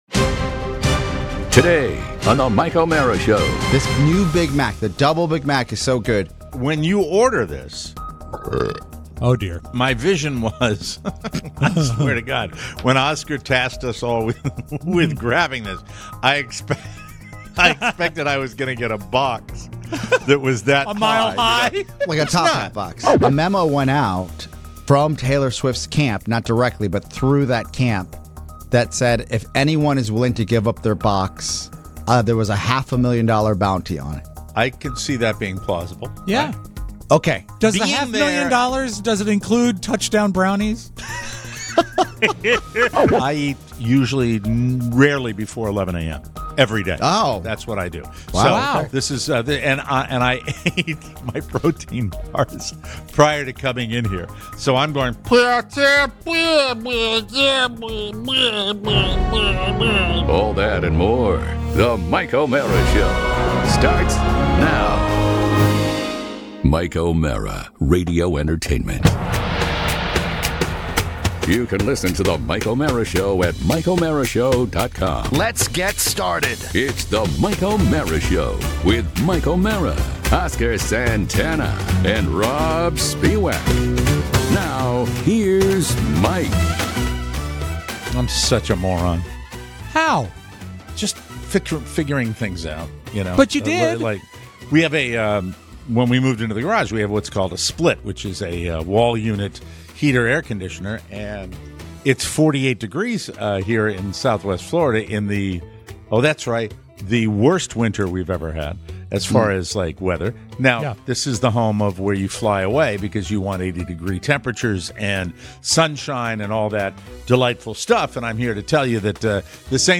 Today, we have a live, real-time, on-air taste test for the Double Big Mac... and the results may surprise you!